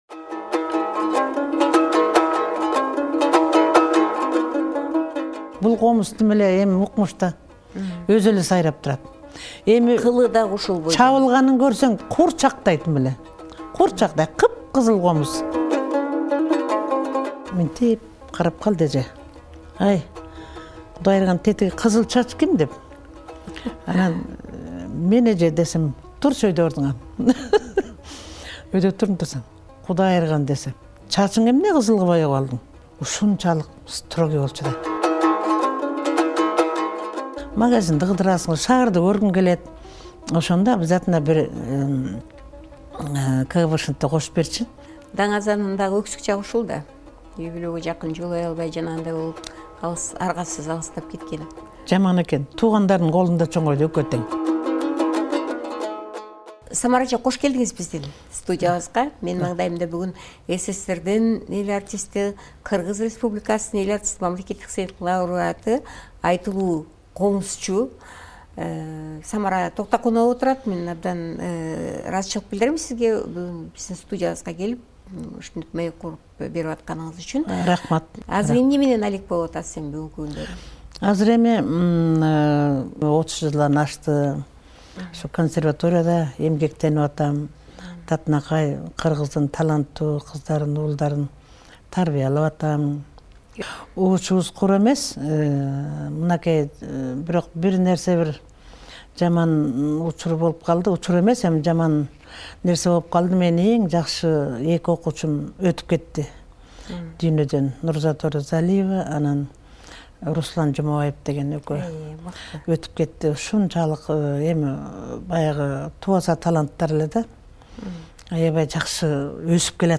"Сыябан" подкастынын коногу - Белгилүү комузчу Самара Токтакунова.